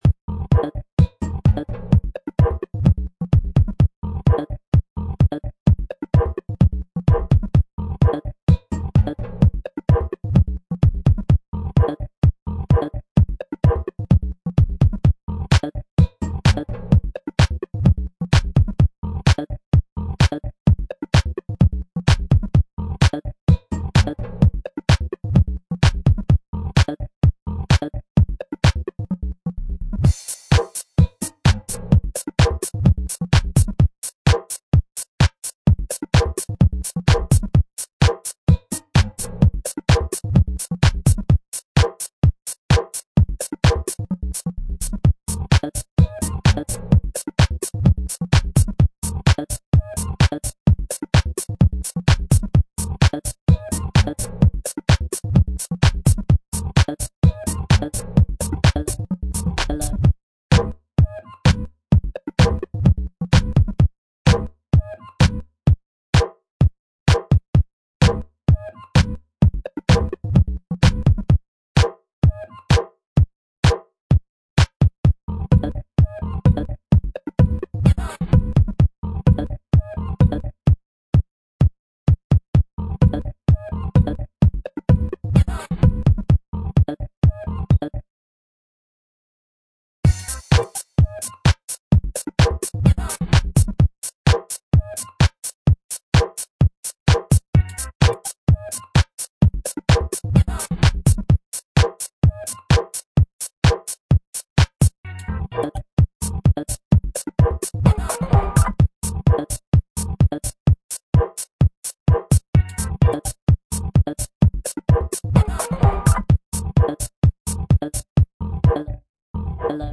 dance/electronic
House
Techno